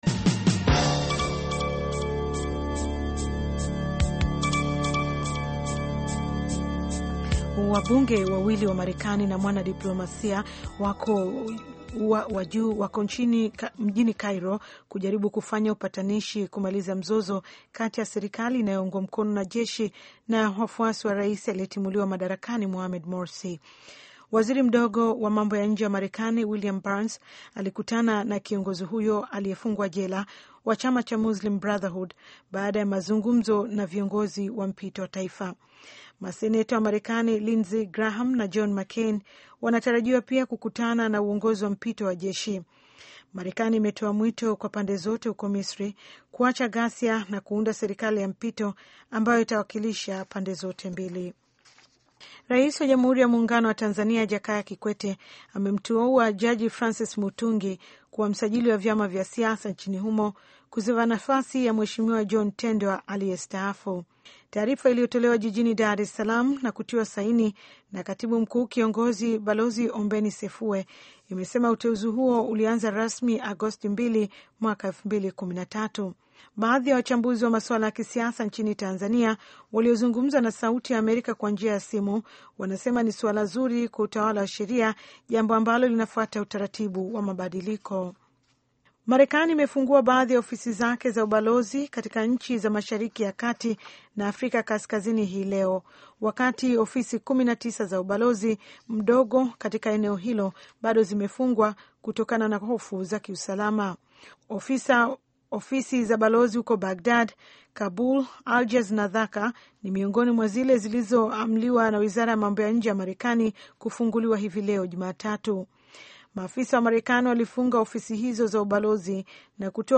Taarifa ya Habari VOA Swahili - 6:03